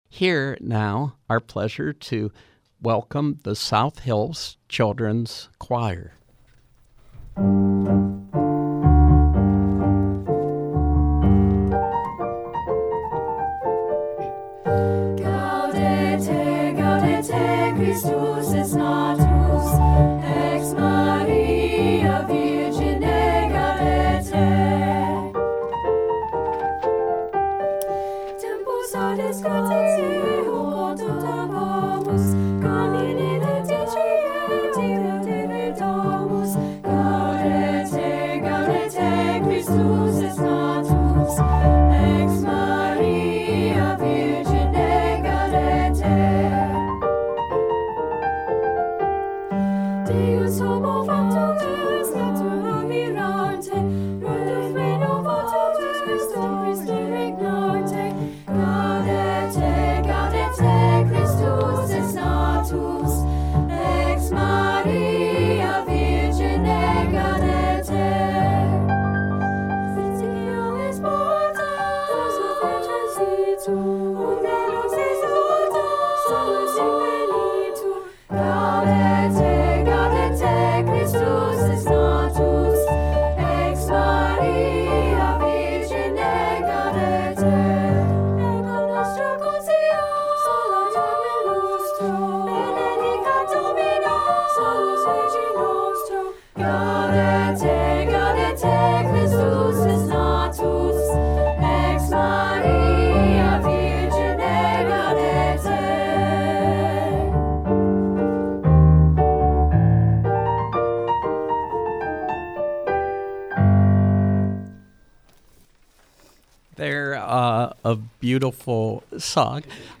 From 12/07/2019: South Hills Children’s Choir previewing its Holiday Concert, 12/13 (7 pm), St. Paul’s Episocal Church, Mt. Lebanon
South Hills Children’s Choir on SLB